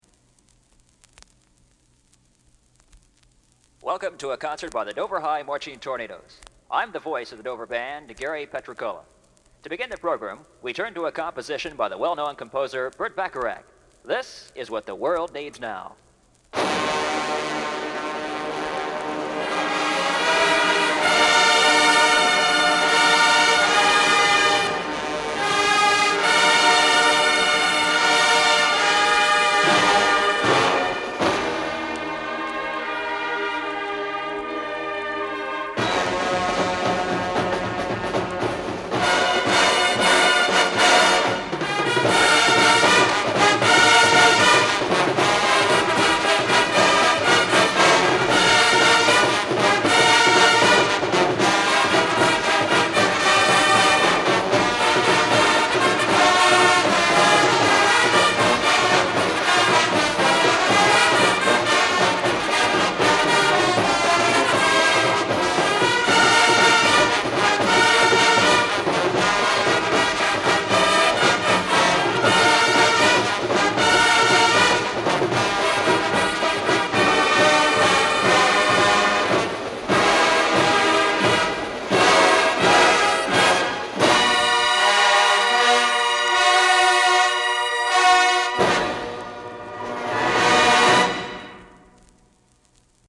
Marching Tornados Band
1973 Marching Tornados Band LP Recording